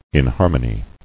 [in·har·mo·ny]